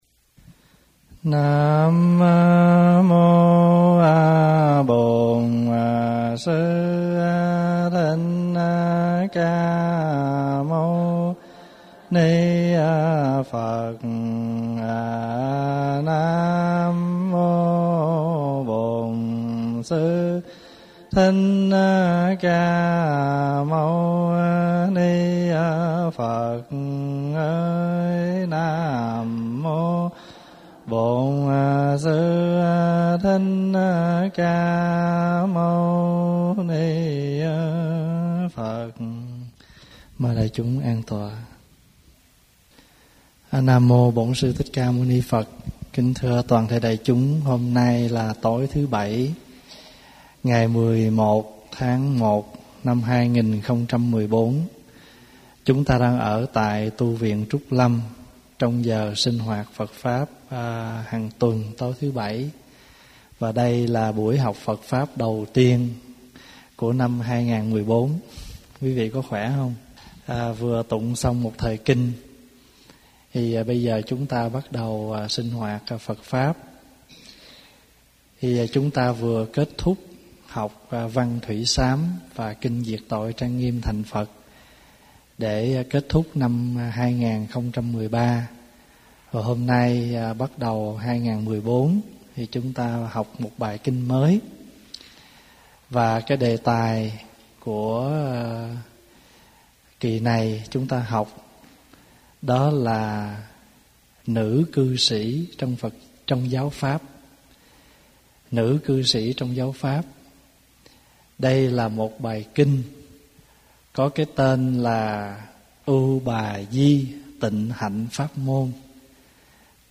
CDs - Nữ Cư Sĩ Trong Giáo Pháp (Kinh Ưu Bà Di Tịnh Hạnh) - Các Băng Giảng CD - Tu Viện Trúc Lâm - Viện Phật Học Edmonton